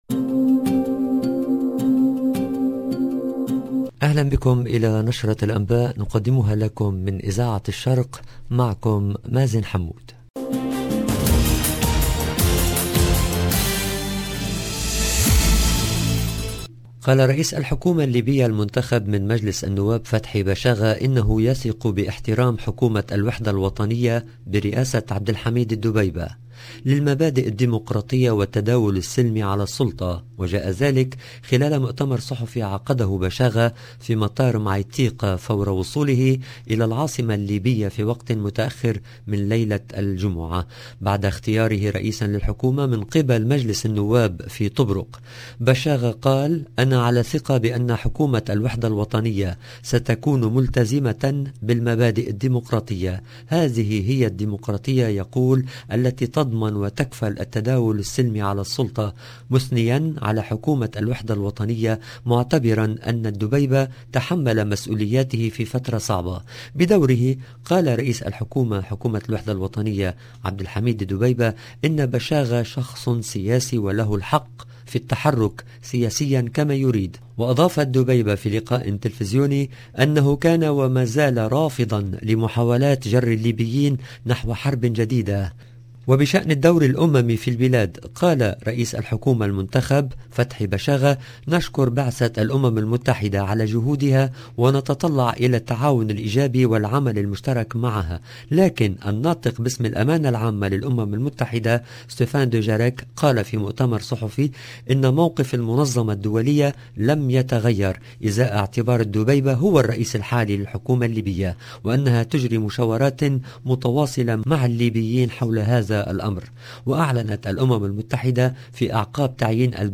LE JOURNAL DU SOIR EN LANGUE ARABE DU 11/02/22